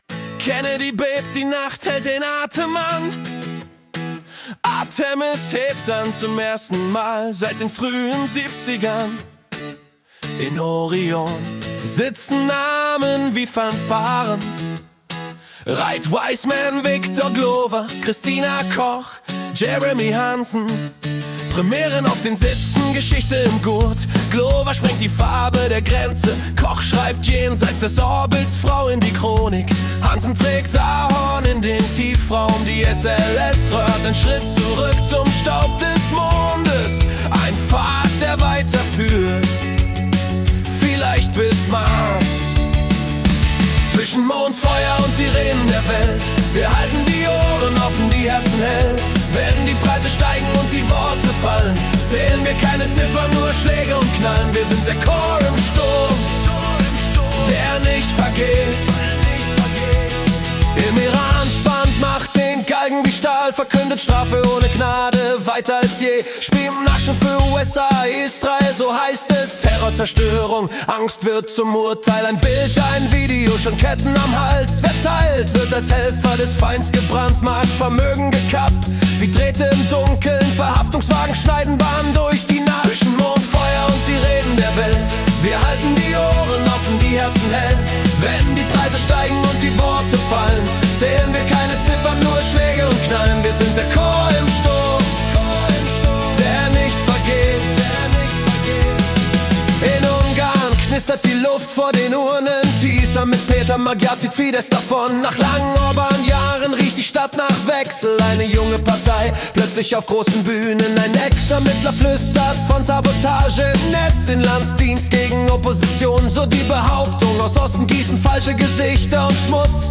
Die Nachrichten vom 1. April 2026 als Rock-Song interpretiert.
Erlebe die Geschichten der Welt mit fetzigen Riffs und kraftvollen Texten, die Journalismus...